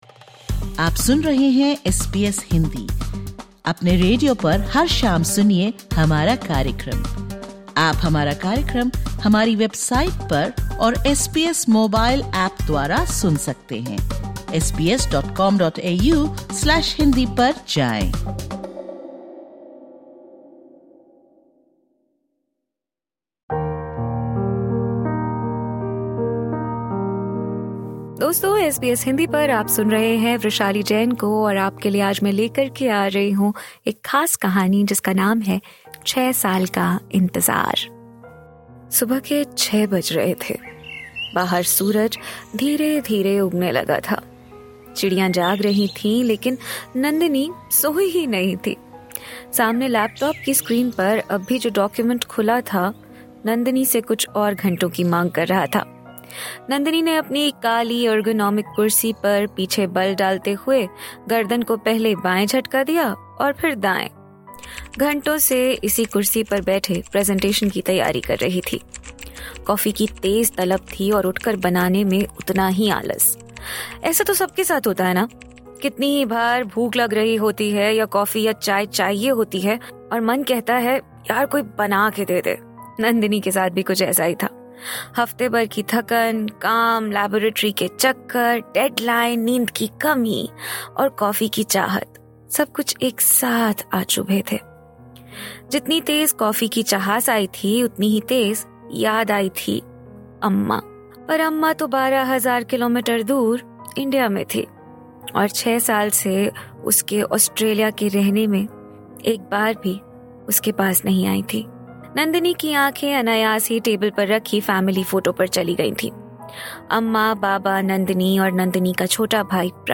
किस्सा-कहानी: सुनिए रेडियो प्रस्तुति 'छः साल का इंतज़ार'